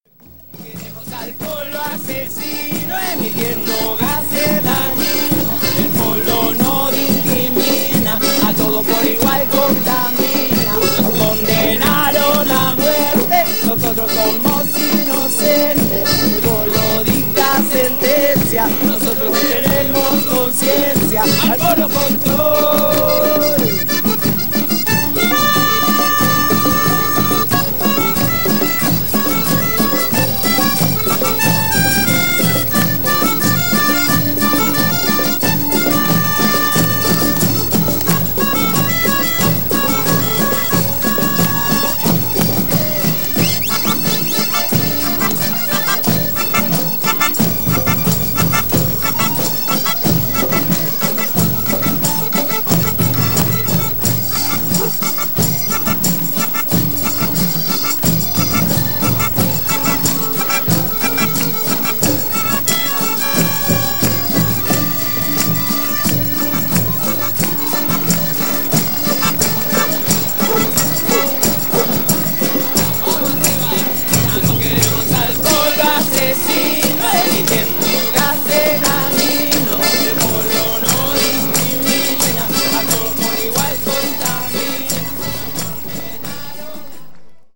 Recorded by Sonoteca Bahia Blanca in Bahia Blanca, Argentina.